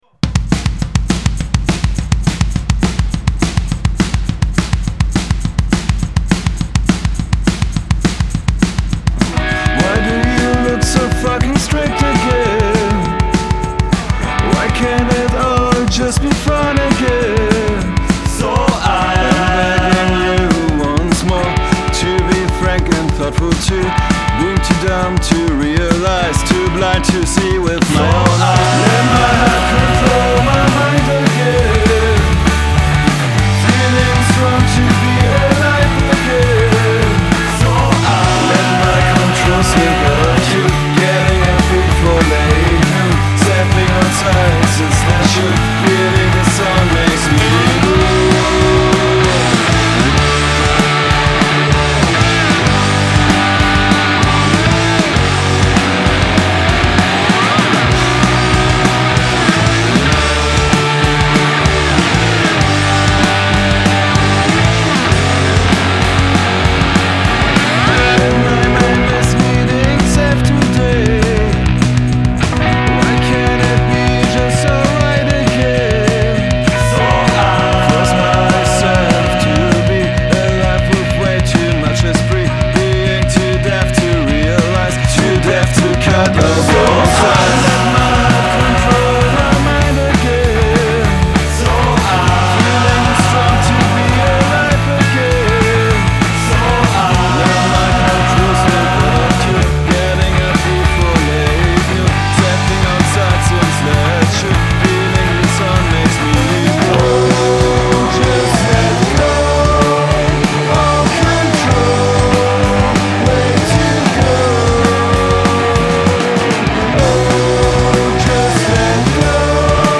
leicht stoneriger Rock mit Slide Guitar, All real